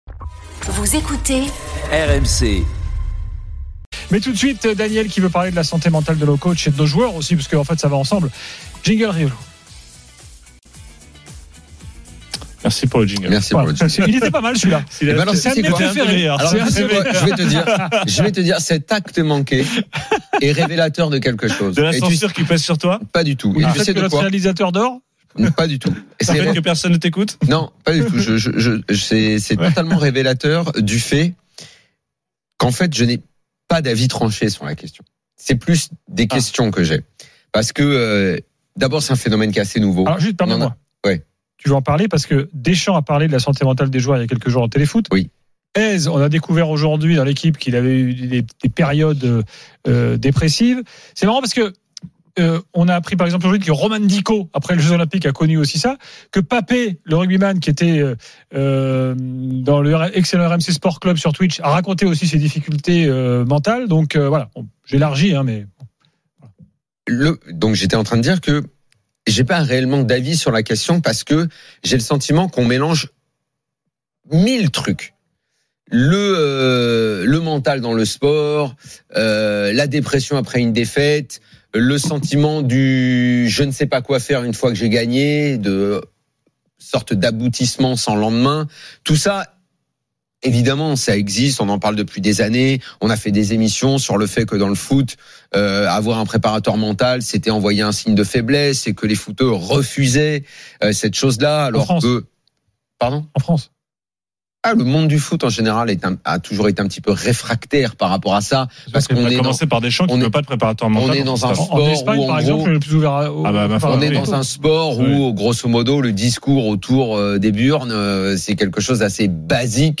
Chaque jour, écoutez le Best-of de l'Afterfoot, sur RMC la radio du Sport !
avec les réactions des joueurs et entraîneurs, les conférences de presse d’après-match et les débats animés entre supporters, experts de l’After et auditeurs RMC.